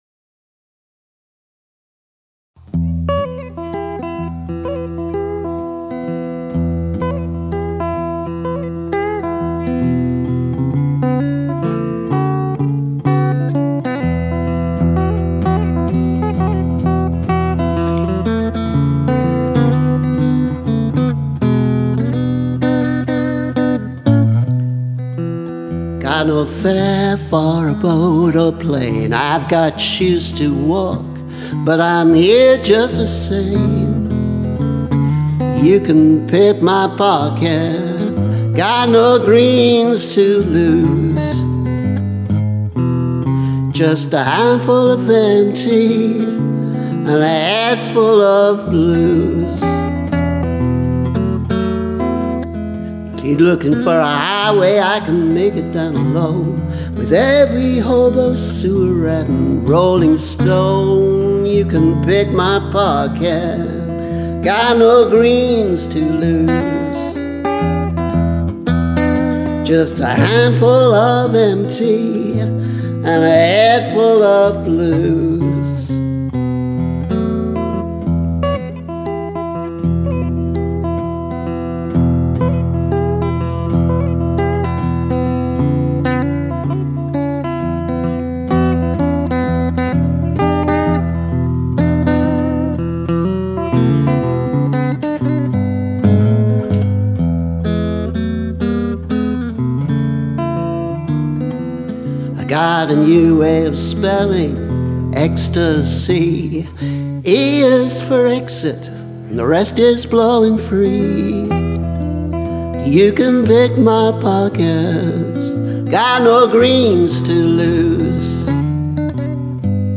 Pick My Pocket [demo]